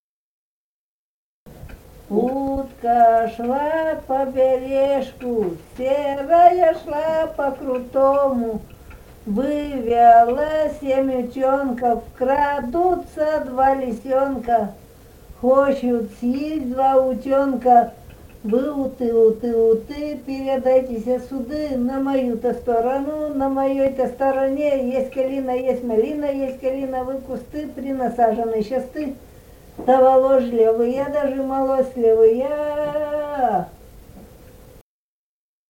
Русские песни Алтайского Беловодья 2 «Утка шла по бережку», игровая плясовая.
Республика Казахстан, Восточно- Казахстанская обл., Катон-Карагайский р-н, с. Белое, июль 1978.